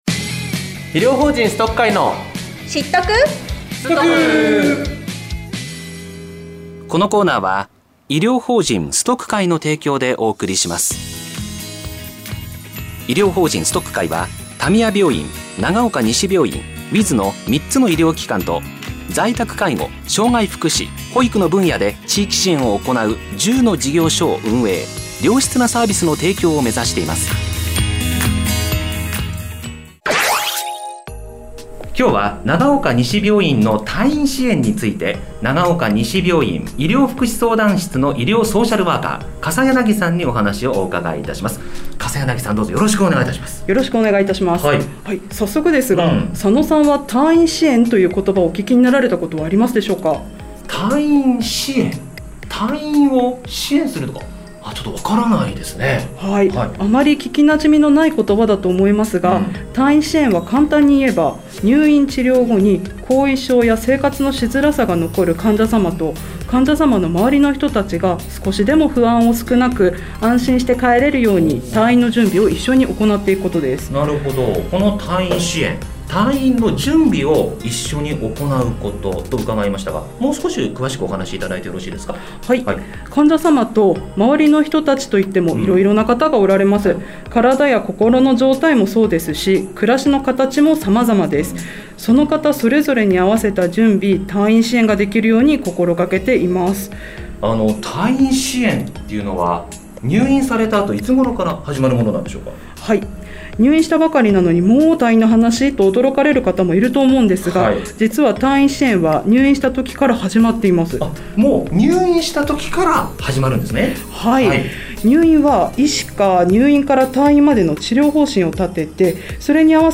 FMながおか（80.7MHz）で放送中の広報番組「知っとく・すとく」